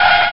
squeel2.ogg